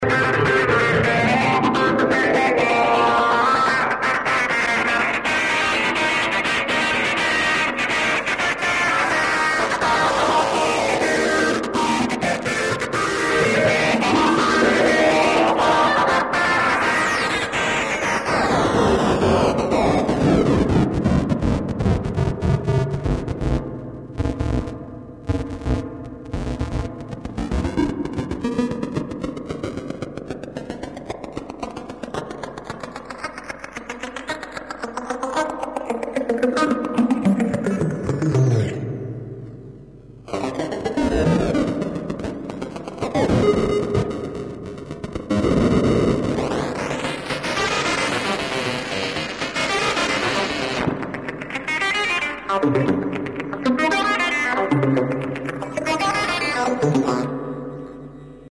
Spring reverb effect unit with limiter system and a special stereo mode.
demo guitar demo 2